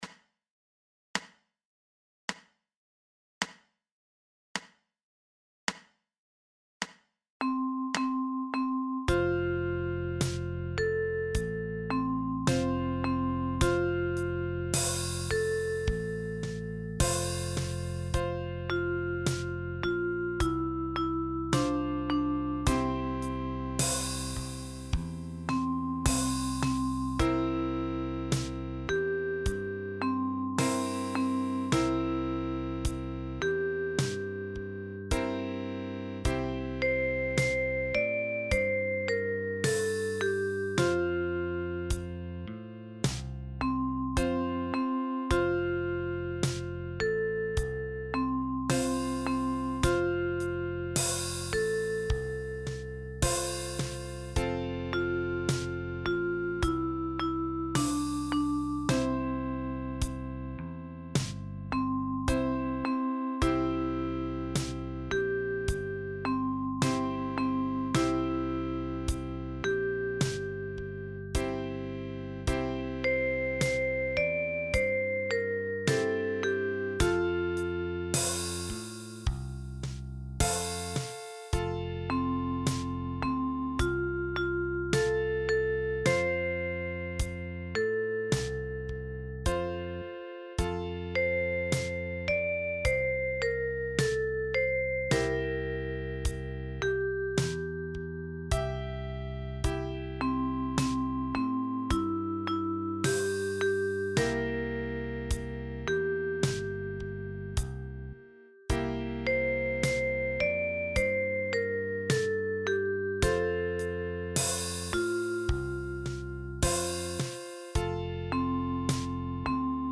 Für Sopran- oder Tenorblockflöte in barocker Griffweise.